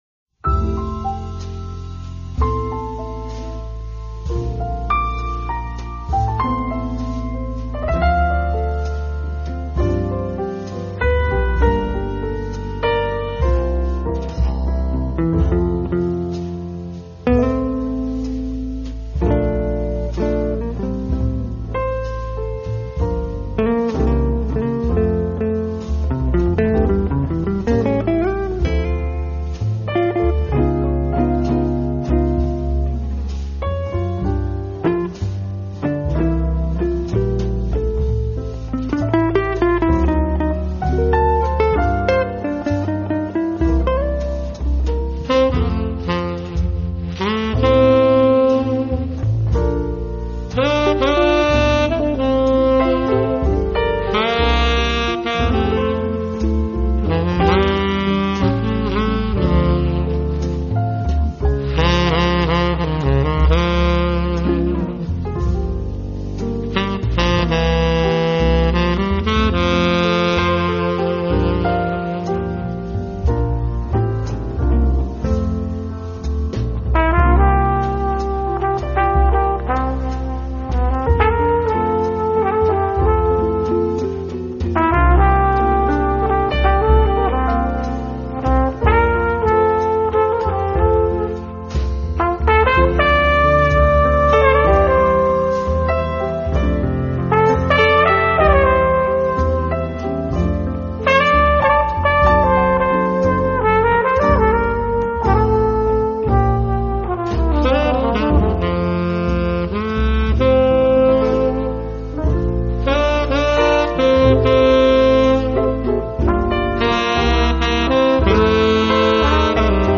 他们把这些原本流行的歌曲改编为带有明显爵士味道的纯音乐，
拥有了爵士音乐特有的浪漫，却没有爵士音乐的吃力和震荡，
这些作品却显得那么的舒缓和柔美，轻盈飘荡间，